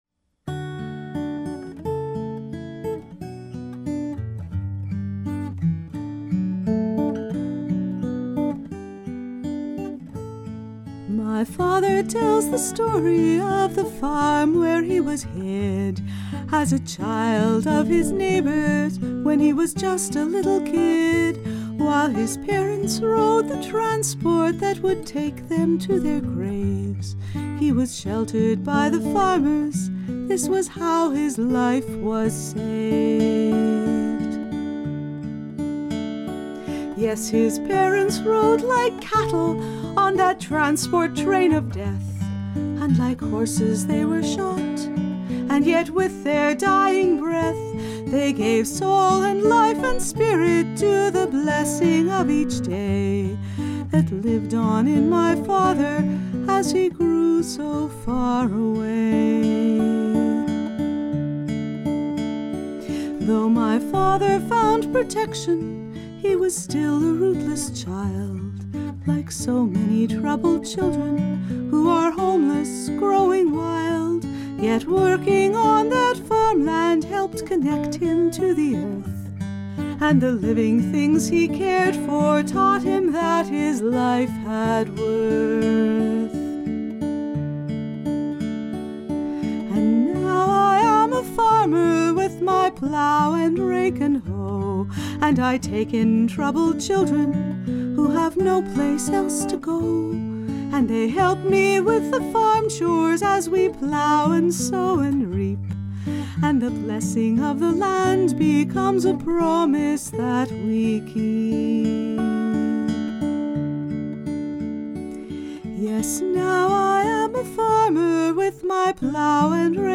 Demo recording